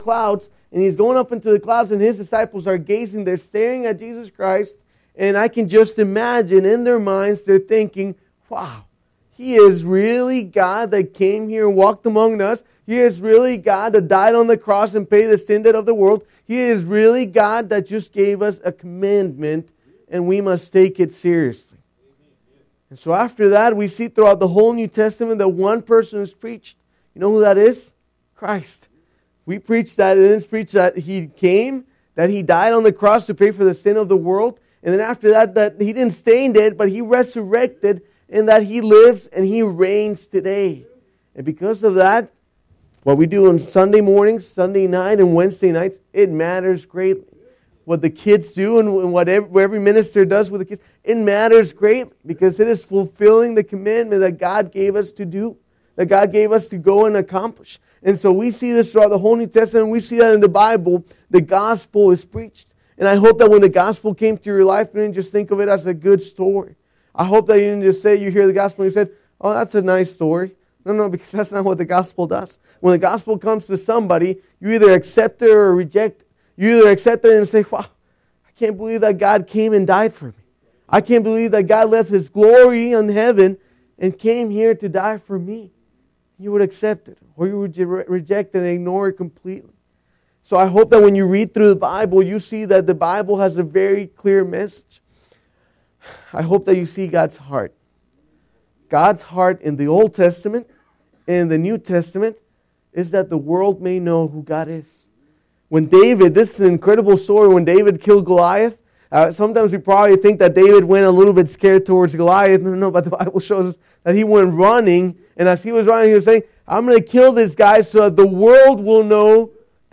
MISSIONARY PM Service 1/10/16